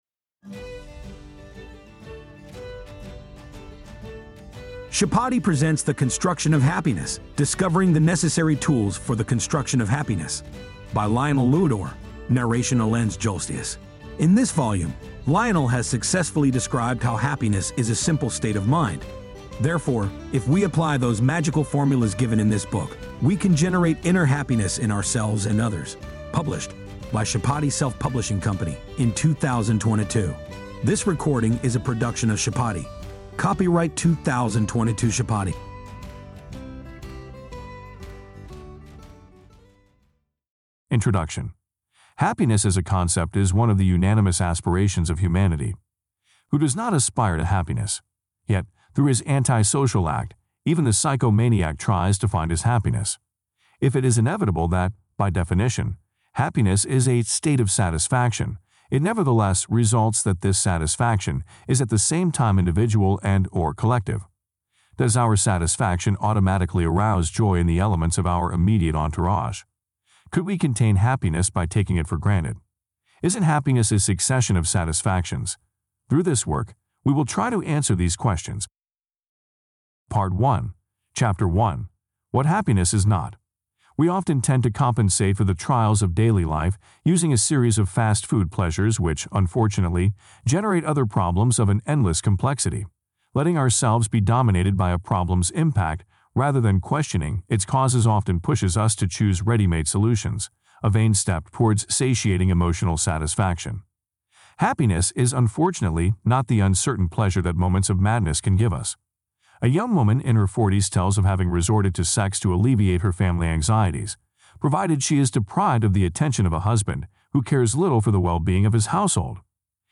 The-construction-of-happiness-discover-the-neccesary-tools-audiobook.mp3